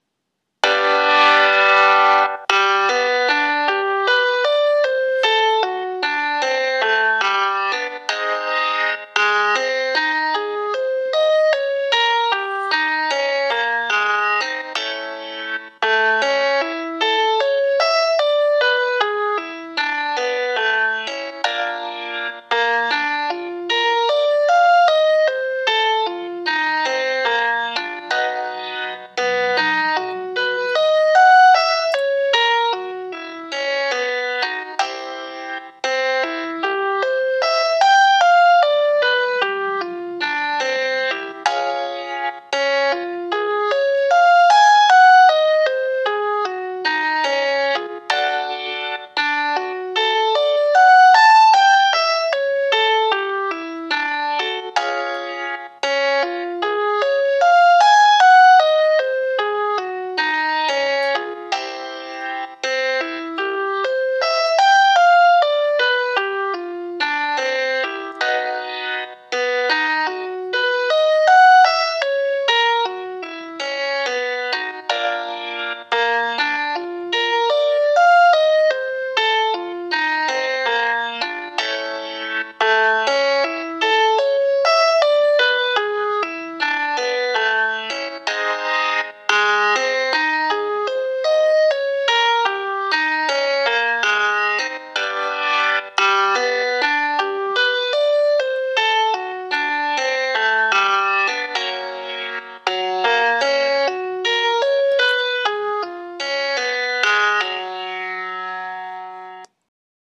FREE VOCAL EXERCISE 1
ESSENTIAL VOCAL WARM UPS